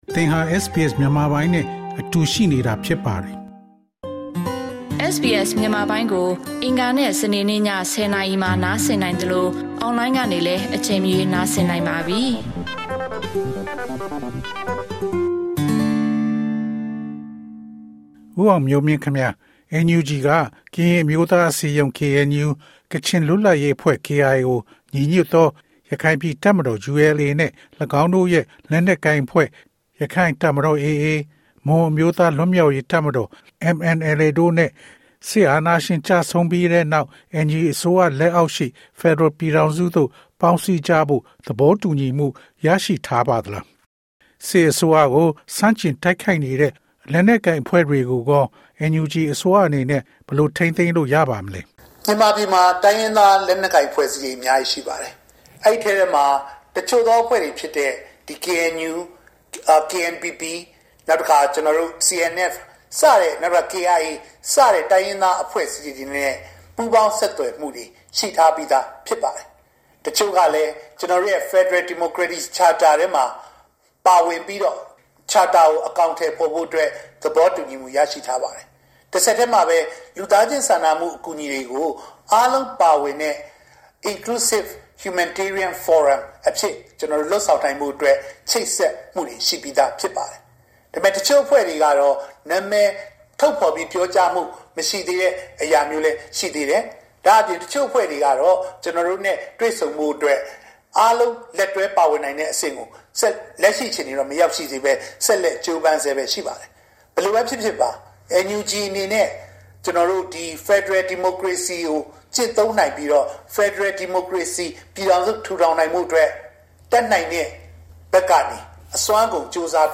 NUG လူ့အခွင့်အရေးဝန်ကြီး ဦးအောင်မျိုးမင်း မေးမြန်းခန်း။